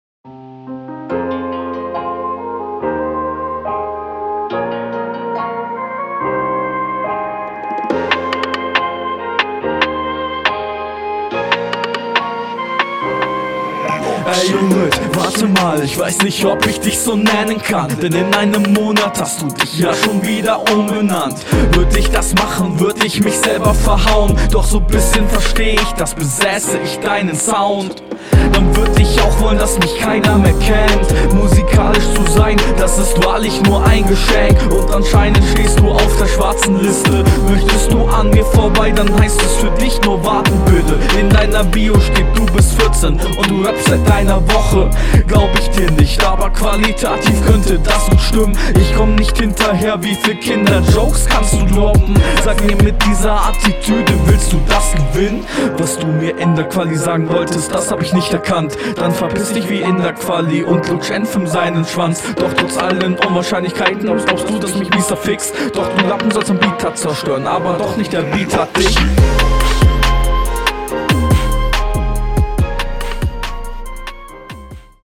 Flow ist ganz okay, aber wo sind die Punchlines?